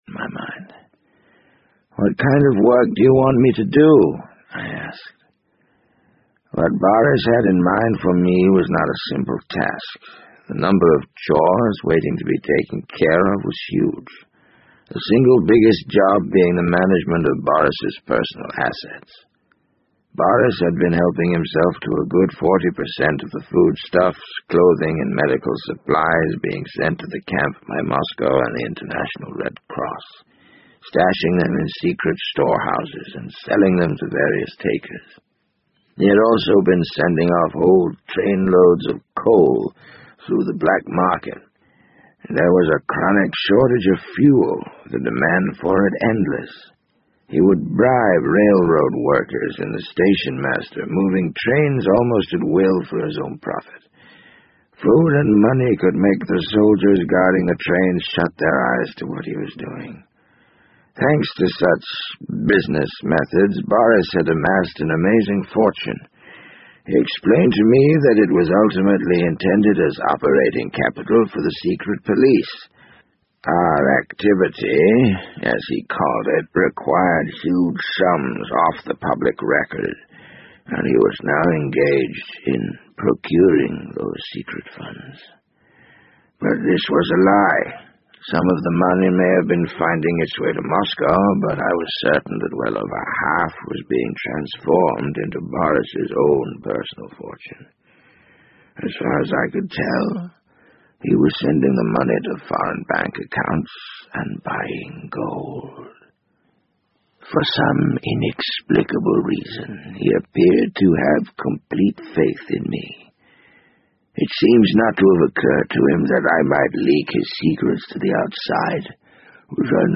BBC英文广播剧在线听 The Wind Up Bird 014 - 15 听力文件下载—在线英语听力室